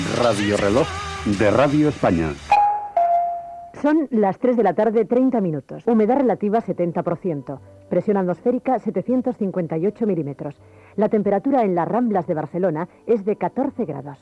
Indicatiu de cada 15 minuts, toc de l'hora i informació meteorològica.
Informatiu